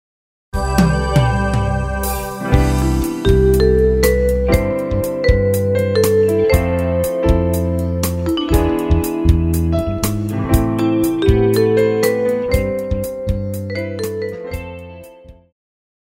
爵士
套鼓(架子鼓)
乐团
演奏曲
融合爵士
独奏与伴奏
有主奏
有节拍器